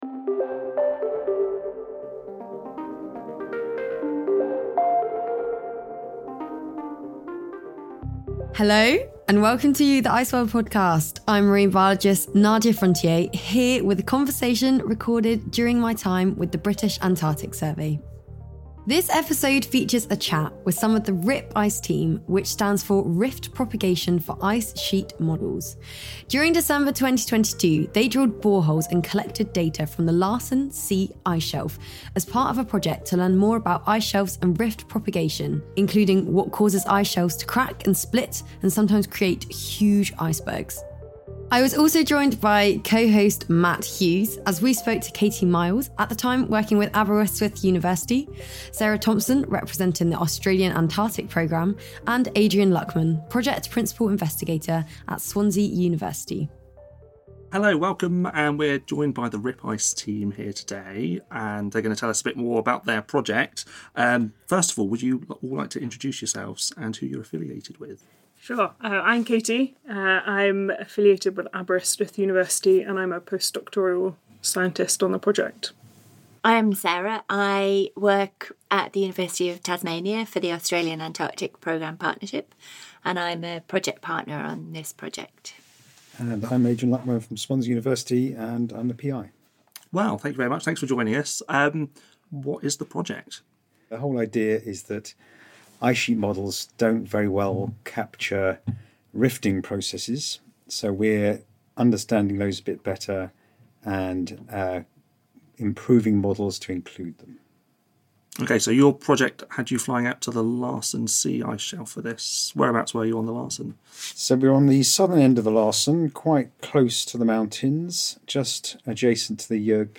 From polar scientists to plumbers, ICEWORLD is a series of interviews with ordinary people who are doing extraordinary jobs in Antarctica. The team talk climate science, extreme living, expeditions and becoming a community.